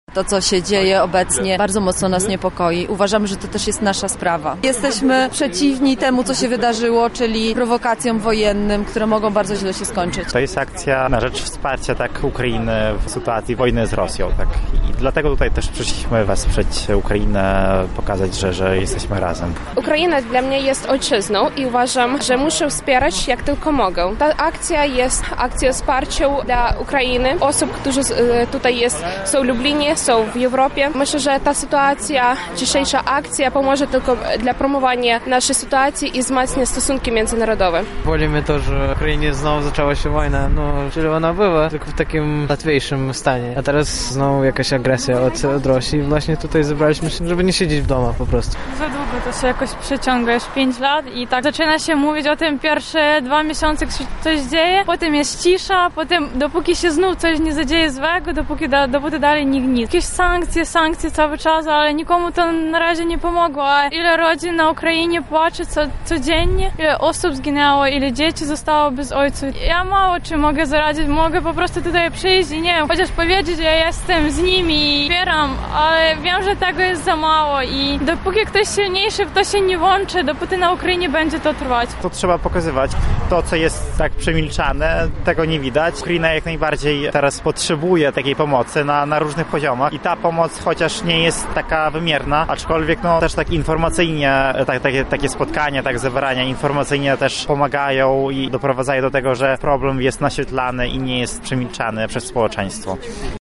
W Lublinie odbył się protest antywojenny.
Na miejscu był nasz reporter: